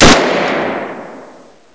assets/ctr/nzportable/nzp/sounds/weapons/m1garand/shoot.wav at 939d7b216bfced8cb6f5c00ab9a51672a79ae2fd
PSP/CTR: Also make weapon and zombie sounds 8bit
shoot.wav